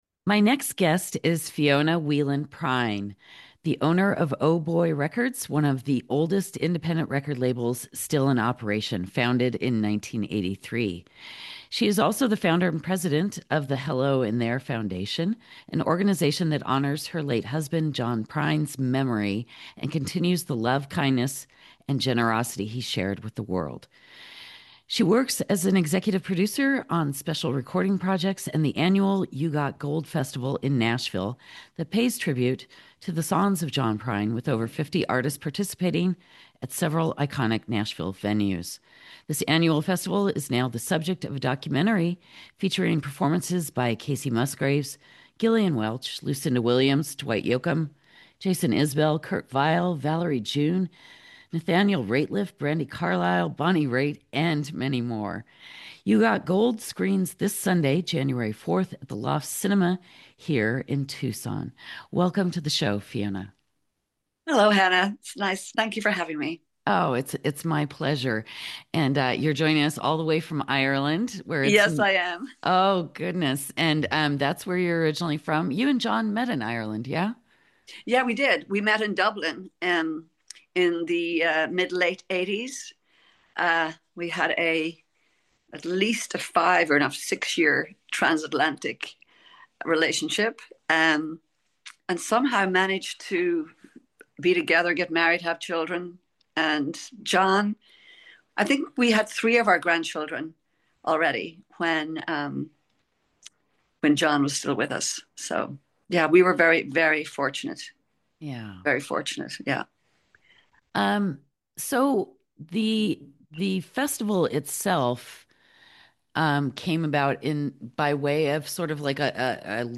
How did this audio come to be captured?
from her family home in Ireland